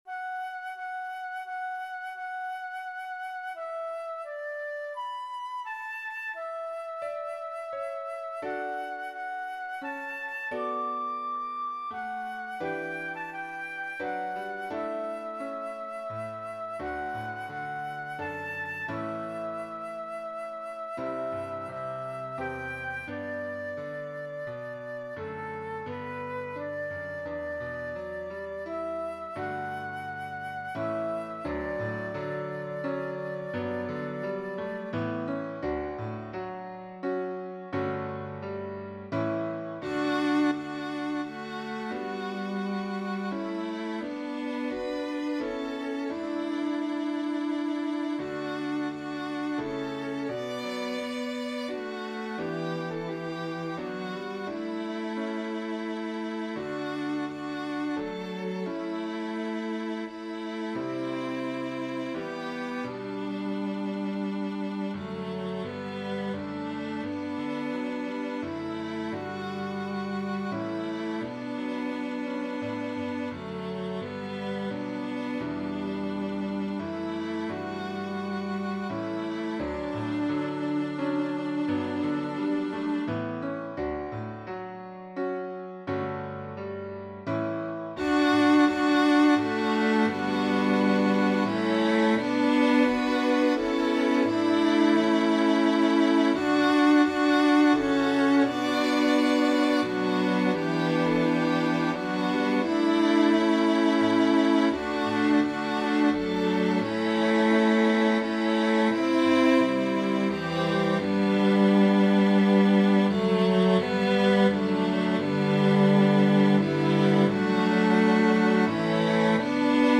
SATB
Voicing/Instrumentation: SATB We also have other 13 arrangements of " God Loved Us So He Sent His Son ".